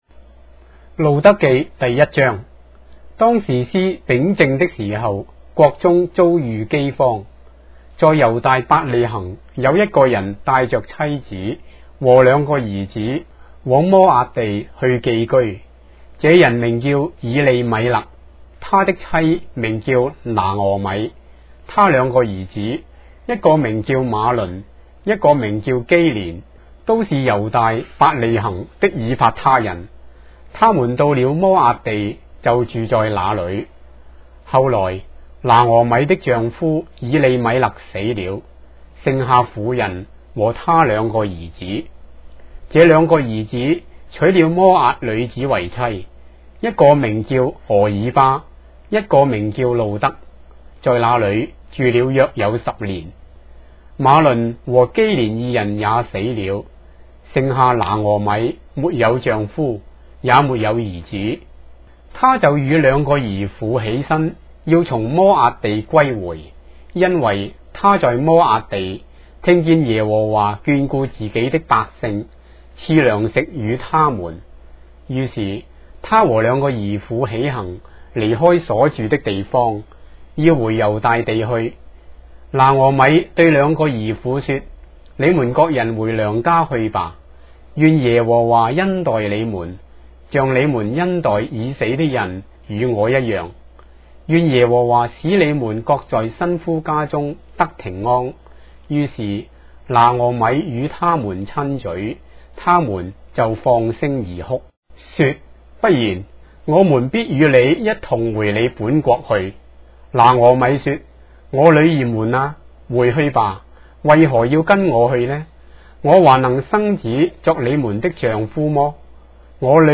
章的聖經在中國的語言，音頻旁白- Ruth, chapter 1 of the Holy Bible in Traditional Chinese